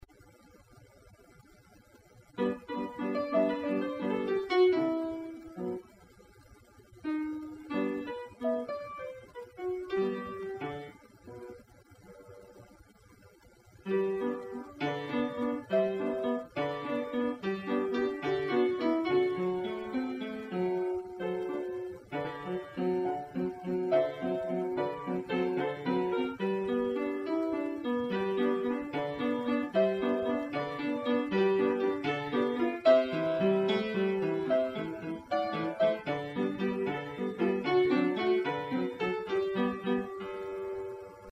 Pour piano :